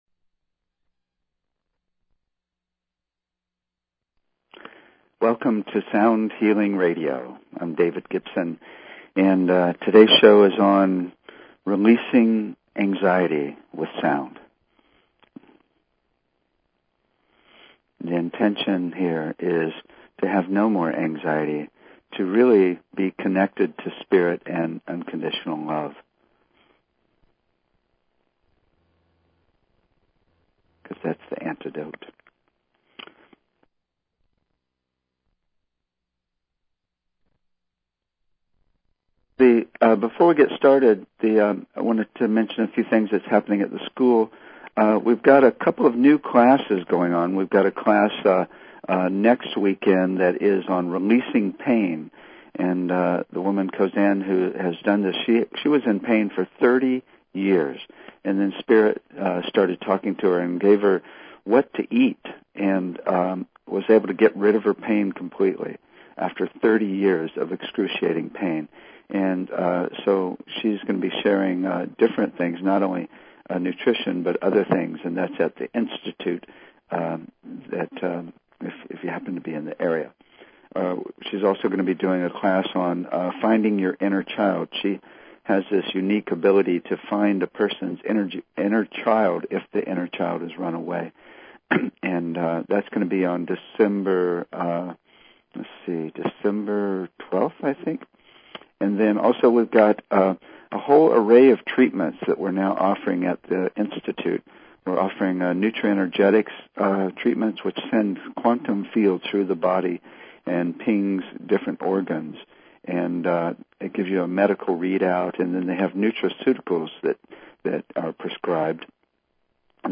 Talk Show Episode, Audio Podcast, Sound_Healing and Courtesy of BBS Radio on , show guests , about , categorized as
We will also do meditations and listen to music that bring us to that still point after the sound ends.